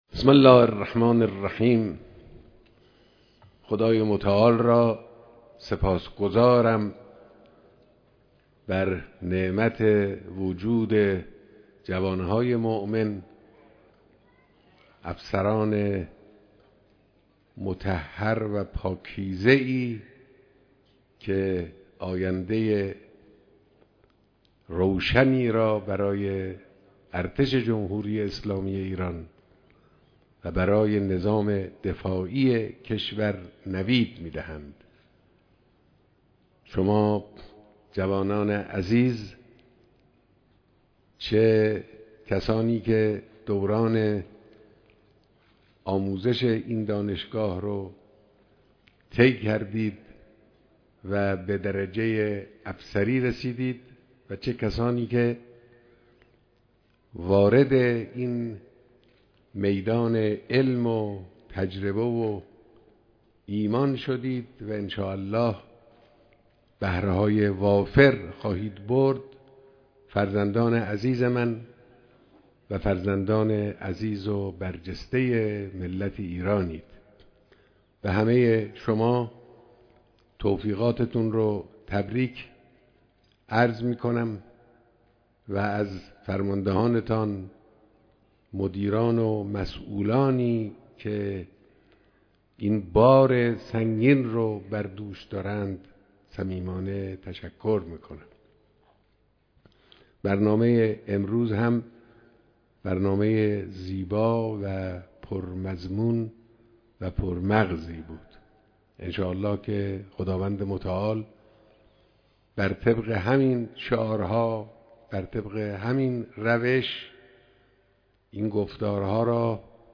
بیانات در مراسم دانش آموختگی دانشجویان دانشگاه‌های افسری ارتش